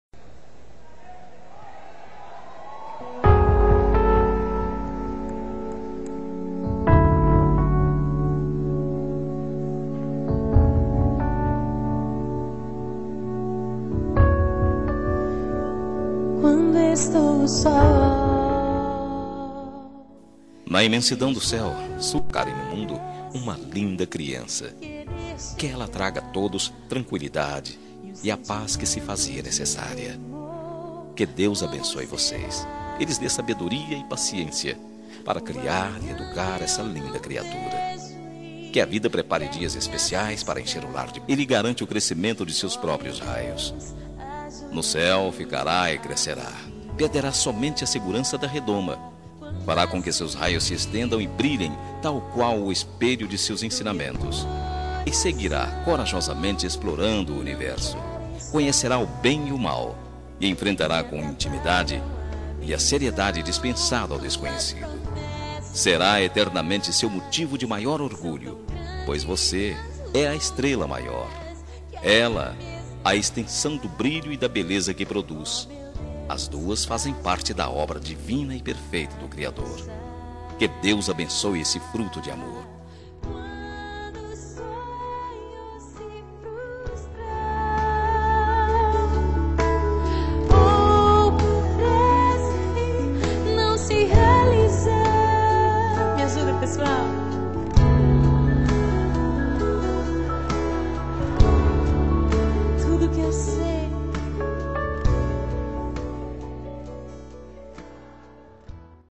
TELEMENSAGEM EVANGÉLICA MATERNIDADE
Voz Masculina